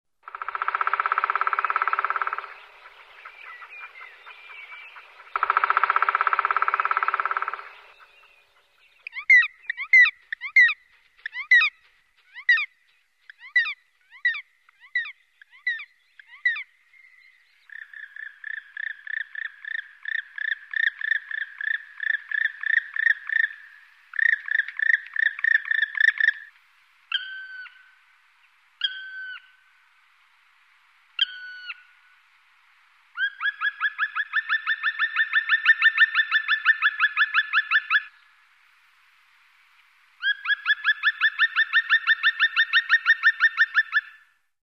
На этой странице собраны звуки дятла: от ритмичного стука по дереву до редких голосовых сигналов.
Стук дятла по дереву